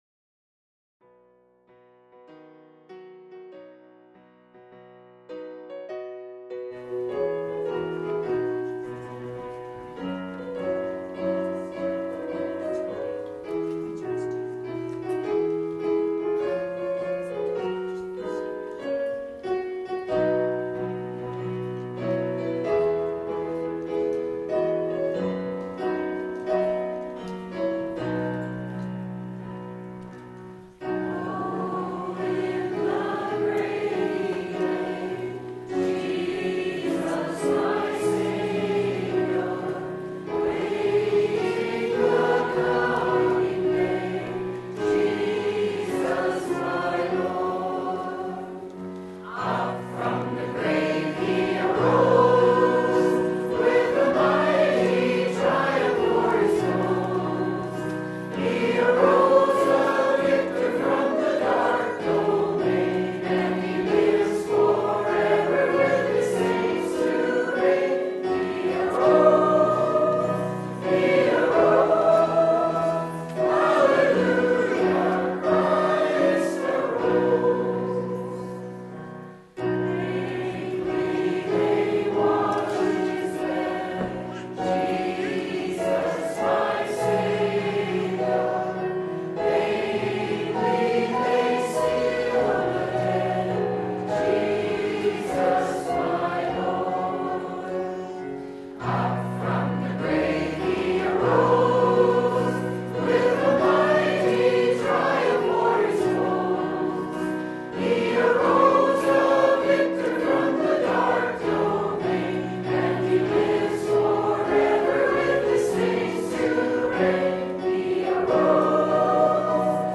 Service Type: Easter